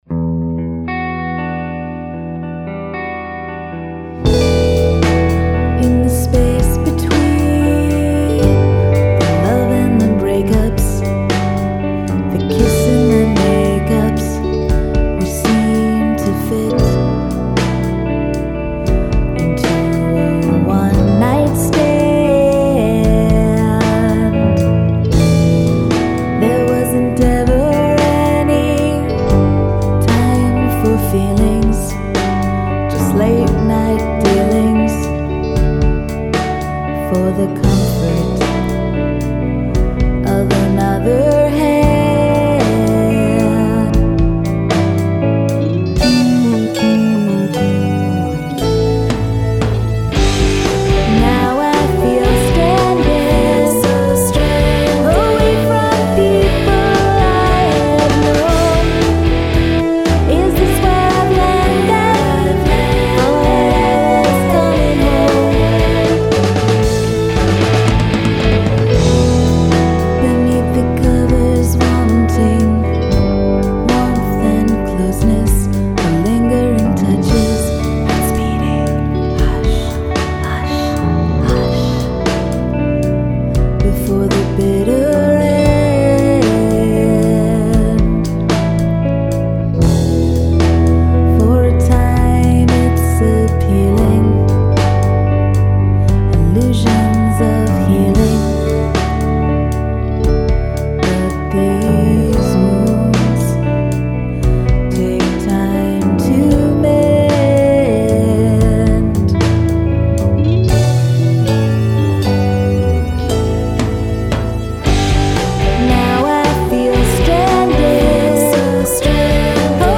Song must include whispering